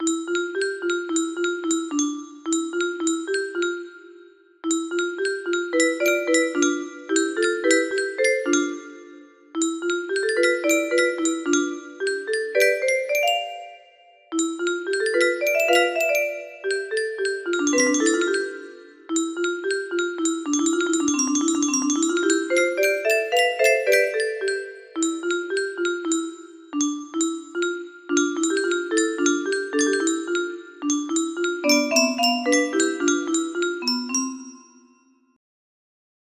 craquement de feuille music box melody